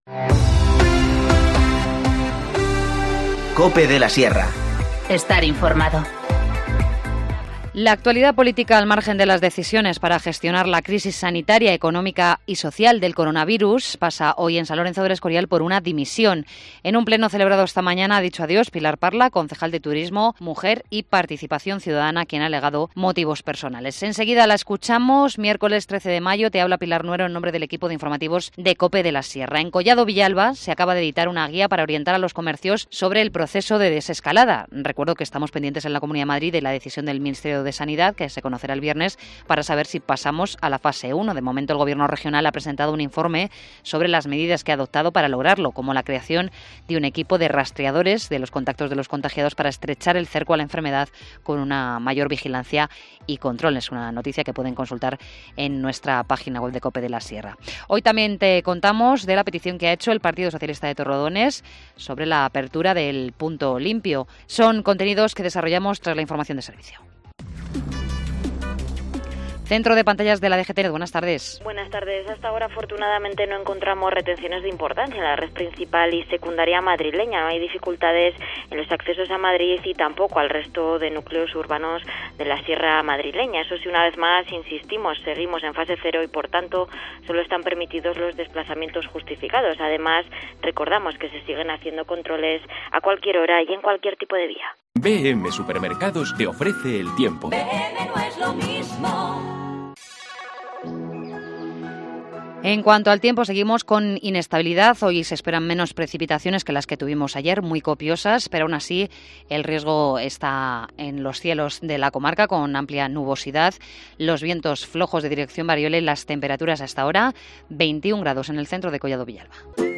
Informativo Mediodía 13 mayo 14:20h